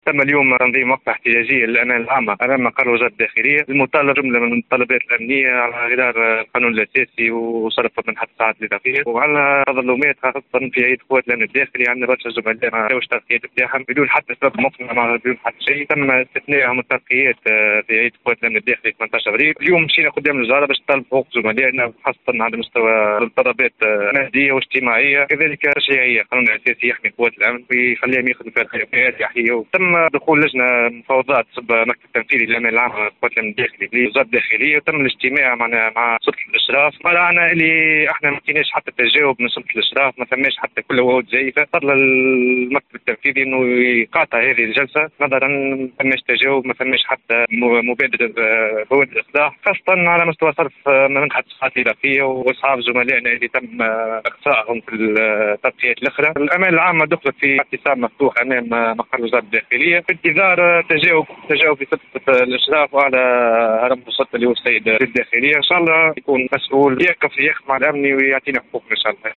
تصريح ل “ام اف ام”